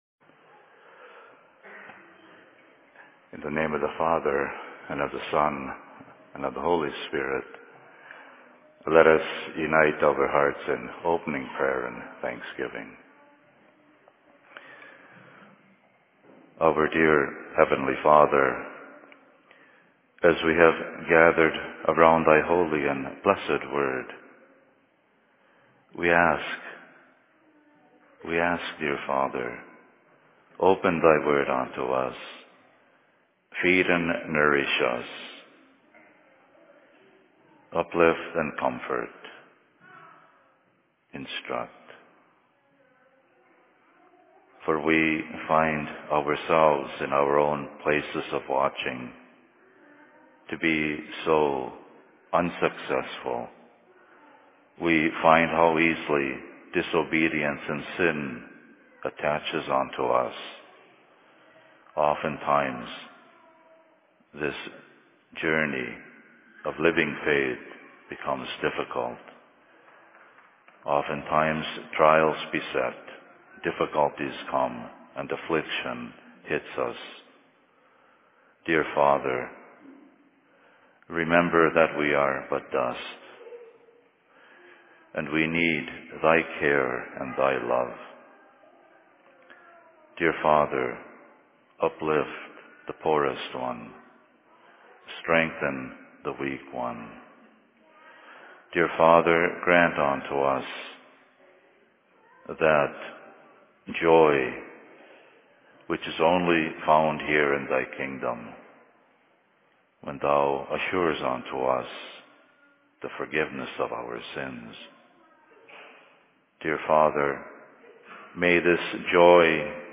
Sermon in Cokato 19.08.2012
Location: LLC Cokato